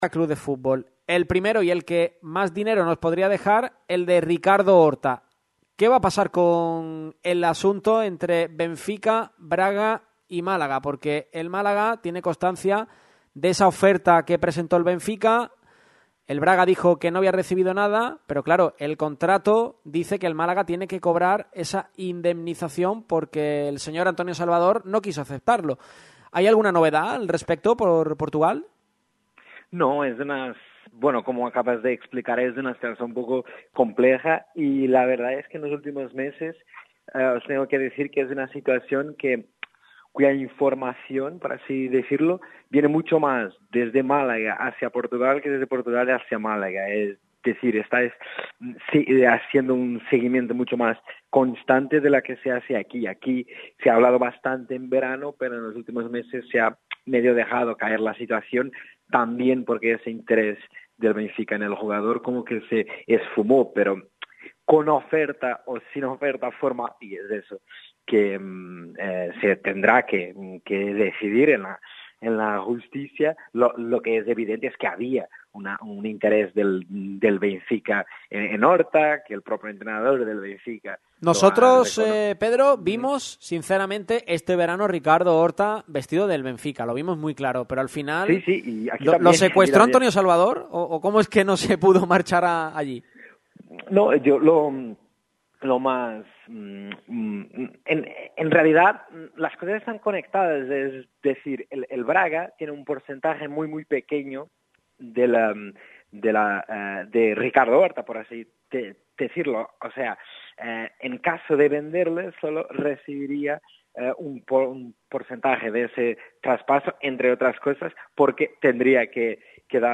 ha estado con nosotros en directo para hablarnos sobre varios futbolistas relevantes en el Málaga CF que se encuentran en Portugal como son Horta